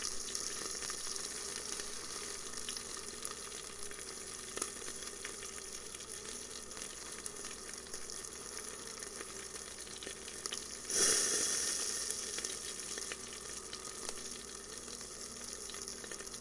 食品和饮料 " 汉堡煎饼
描述：可口！汉堡在锅里嘶嘶作响，我偶尔会稍微移动一下。
标签： 厨师 汉堡 铁板 食品 嘶嘶
声道立体声